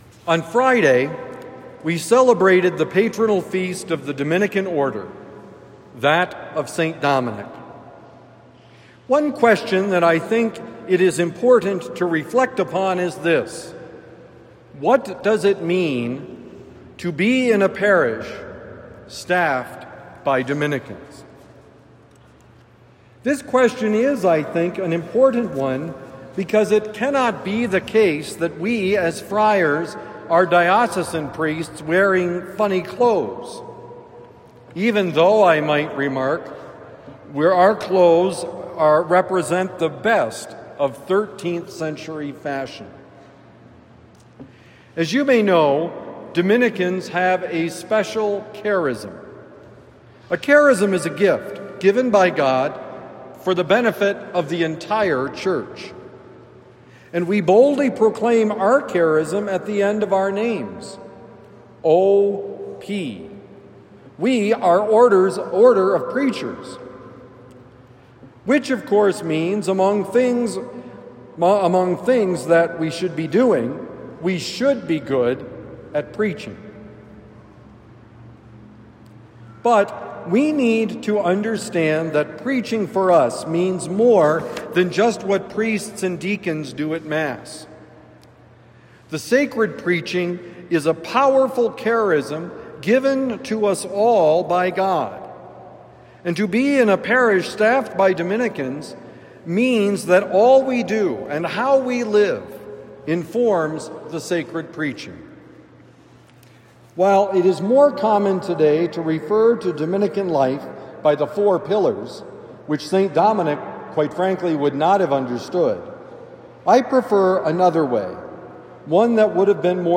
Regular Observance and Sacred Preaching: Homily for Sunday, August 10, 2025